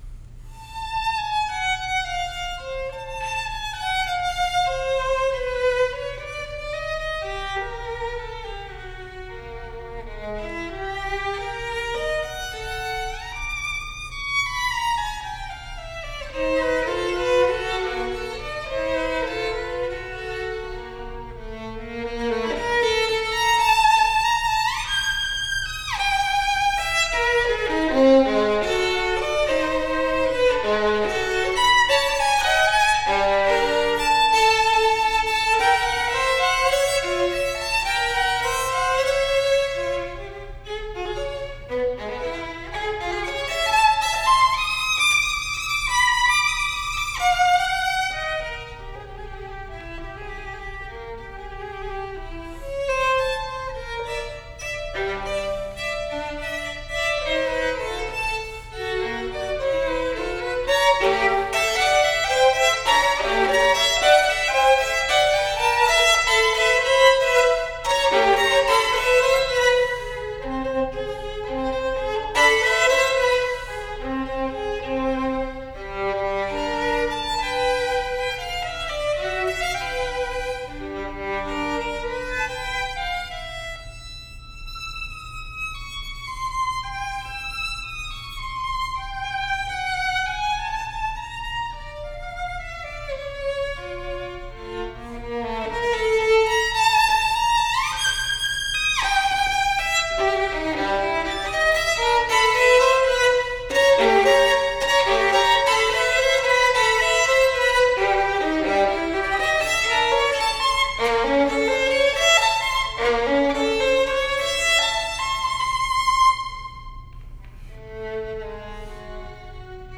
10 Sonatas for Solo Violin
(Moderately Slow)     [2:05]
(Allegro quasi Bartokoid) [1:45]